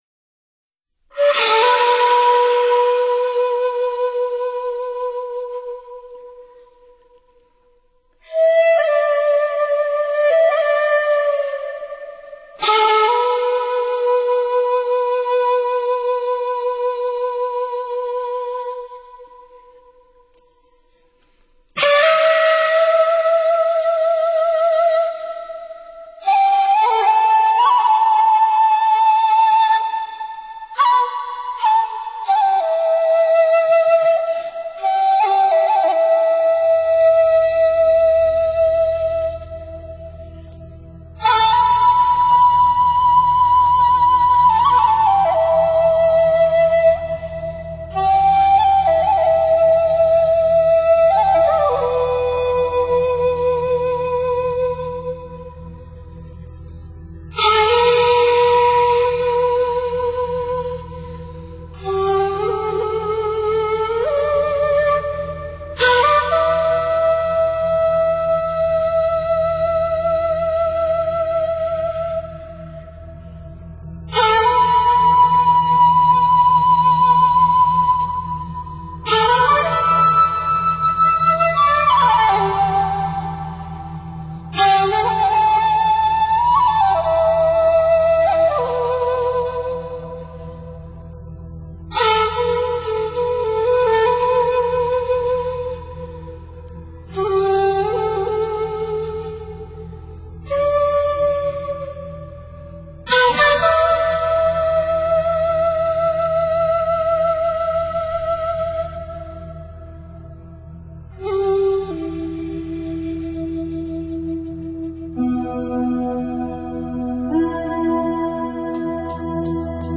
sutra recitation and digital music
melodious and funky, with power and gentleness